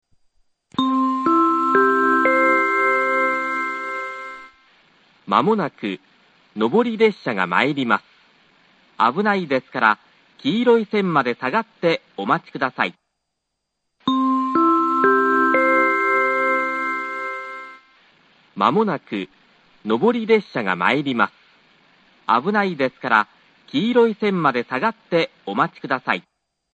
３番線接近放送 ２番線と同じです。